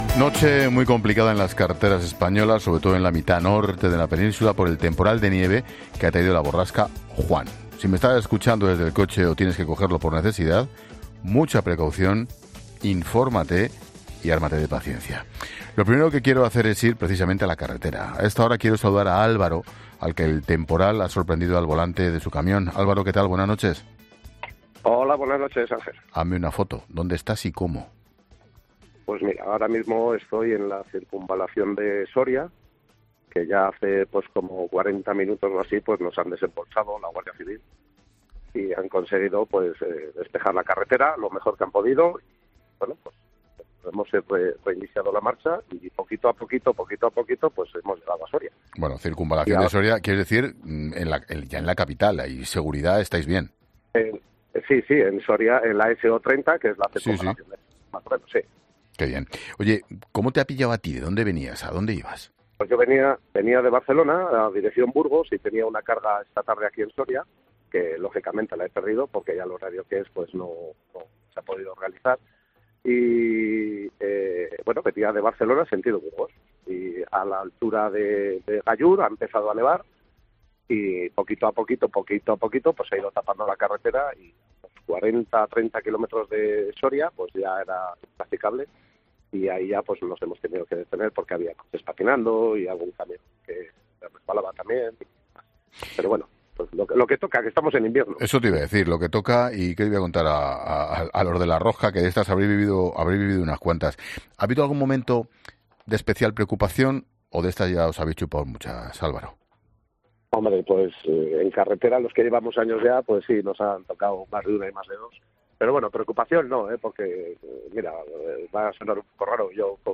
La Linterna habla con dos de los 600 conductores que han estado horas parados en la carretera a consecuencia de la nieve y el temporal